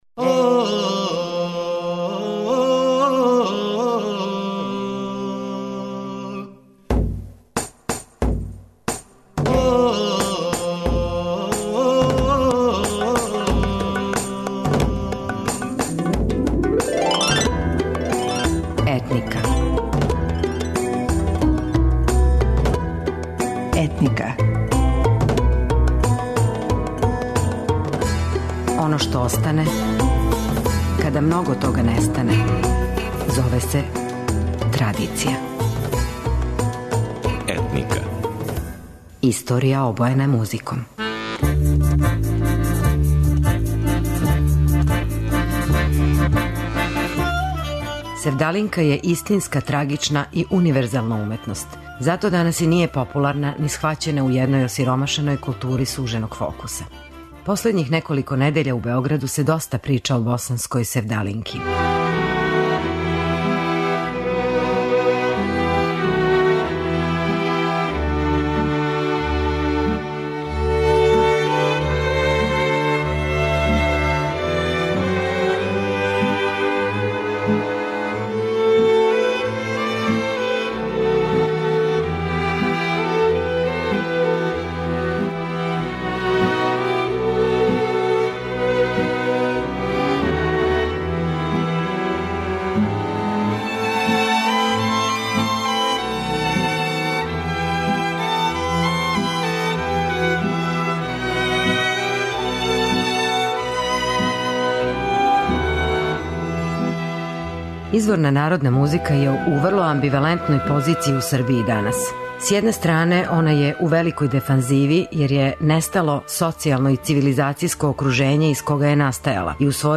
Ова песма се готово увек везује за меланхолично расположење, да би у Босни појам 'севдах' добио значење чежње, љубавног жара, љубавних јада.